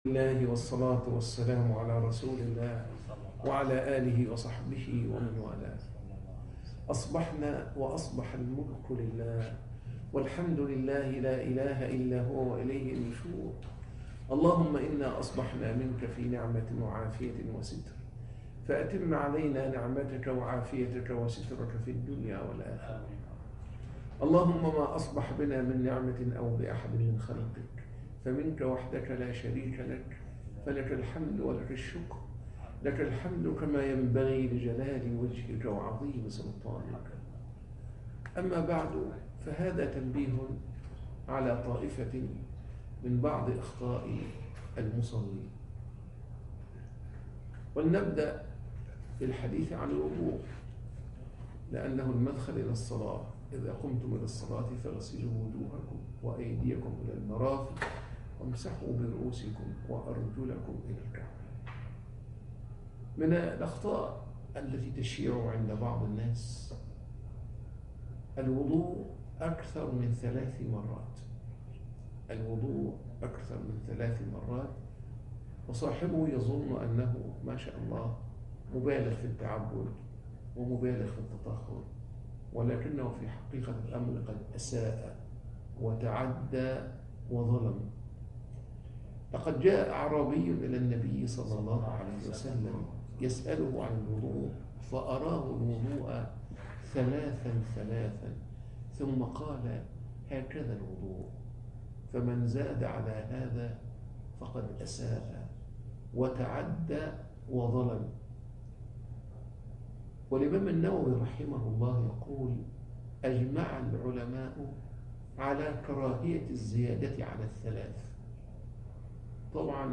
من أخطاء المسلمين (درس بعد الفجر